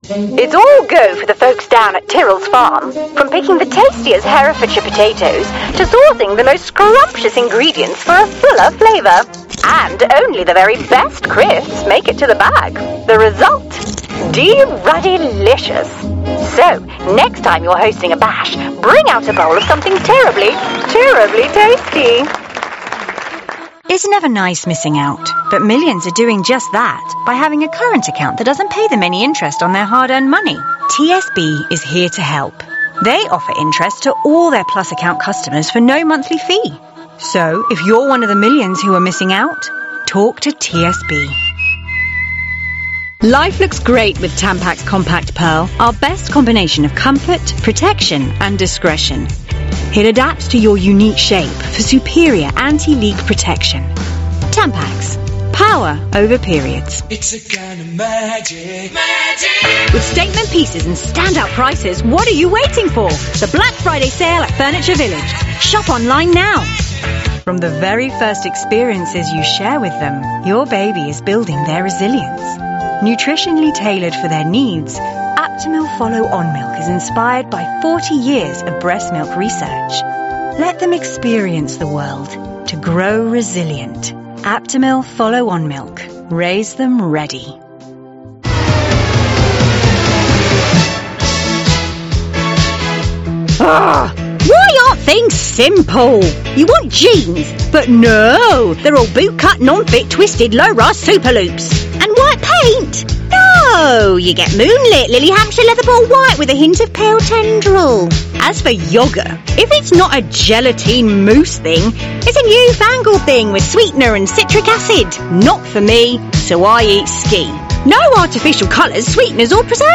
English (British)
Confident
Warm
Authoritative